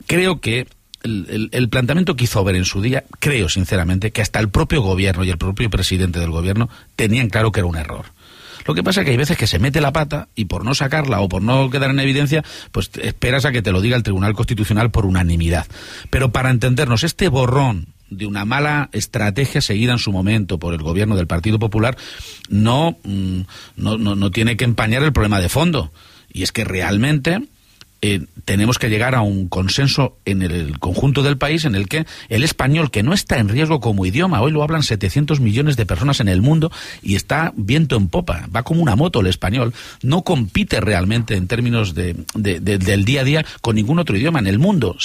El presidente de Castilla-La Mancha, Emiliano García-Page, ha considerado hoy durante una entrevista en el programa 24 Horas de RNE